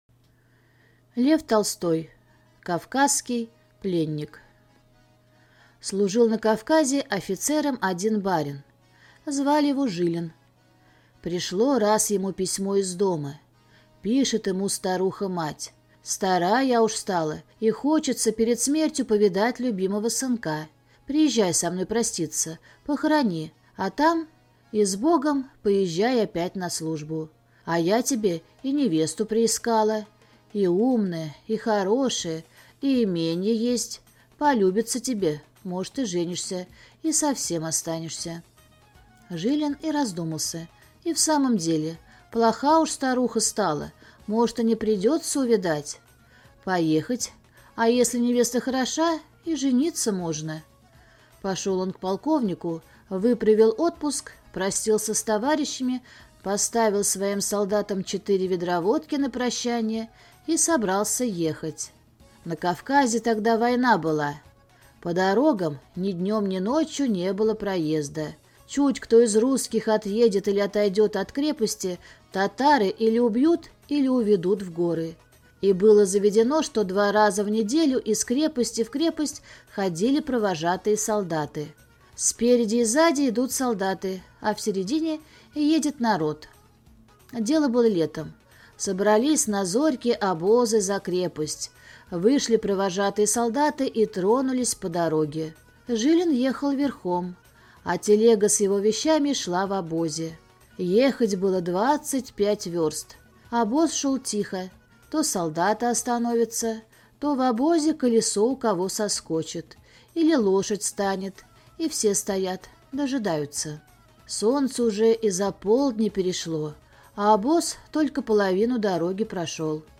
Кавказский пленник - аудио рассказ Толстого Л.Н. Рассказ про двух офицеров, служивших на Кавказе и попавших в плен к татарам.